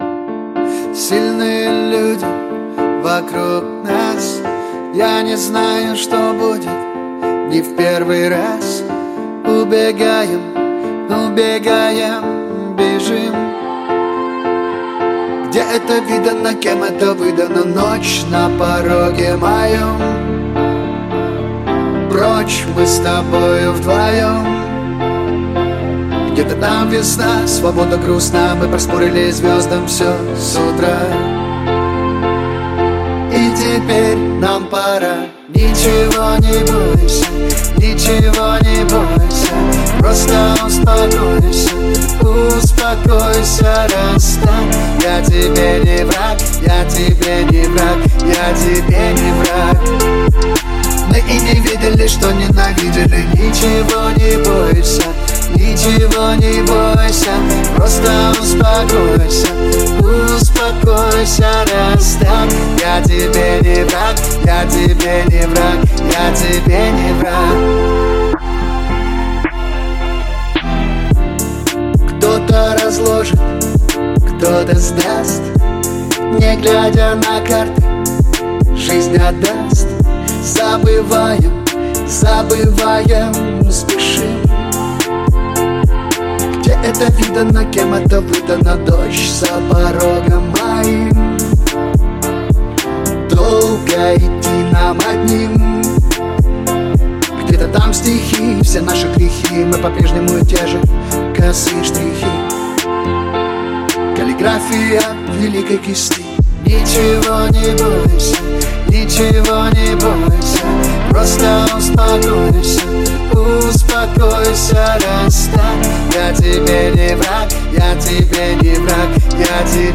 Поп-музыка